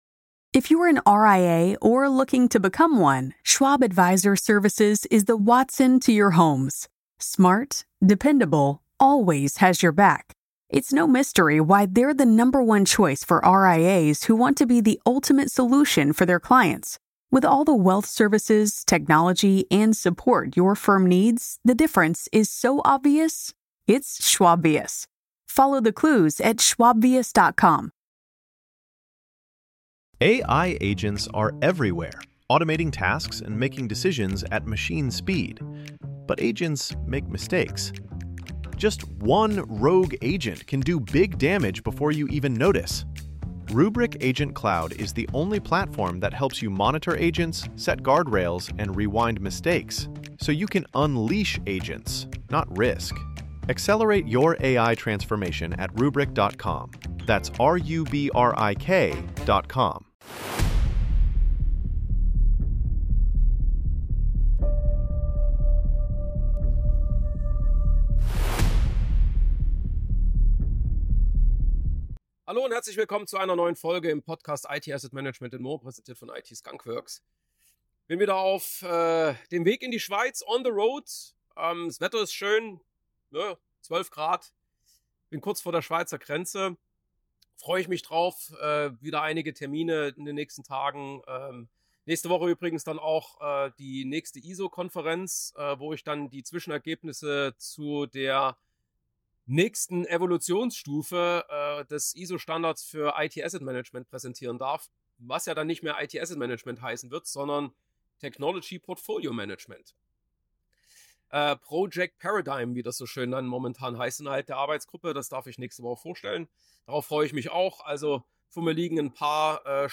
In dieser On-the-road-Episode kurz vor der Schweizer Grenze nehme ich euch mit – Männergrippe inklusive – zu meinen wichtigsten Takeaways vom Forrester Technology & Innovation Summit in den USA.